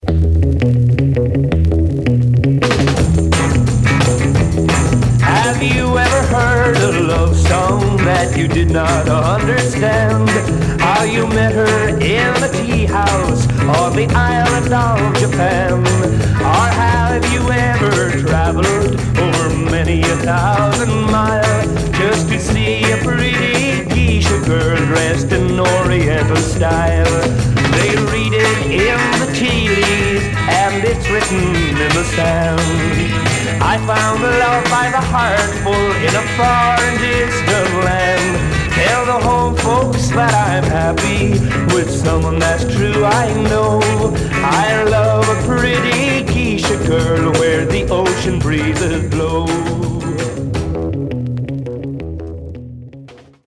Stereo/mono Mono
Garage, 60's Punk